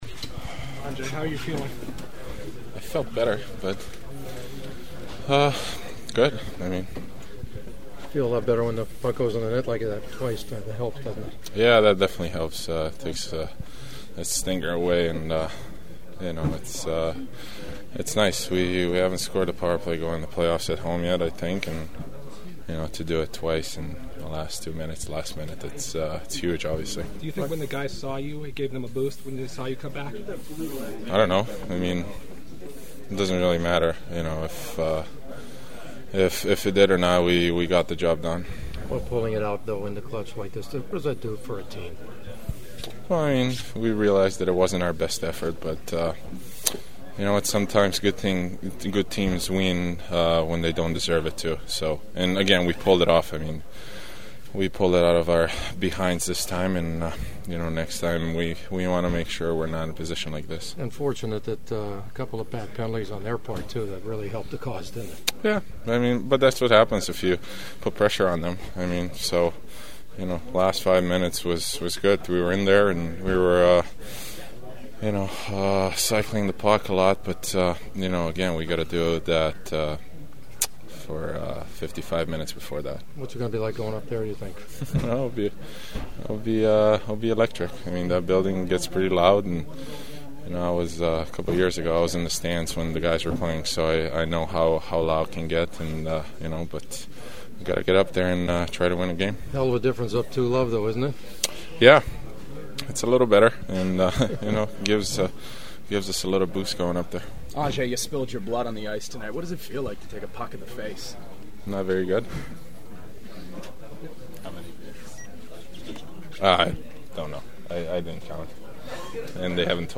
The sounds of the game from the locker room tell a story of a team that’s feeling relieved as much as joy after one of the great playoff wins in their club’s history.
Kings center Trevor Lewis after finding a nice time to score his first goal of the playoffs:
Kings captain Dustin Brown:
Kings center Anze Kopitar who talked with stitches above his mouth after taking a puck in the face but told me it felt much better after those 2 late goals: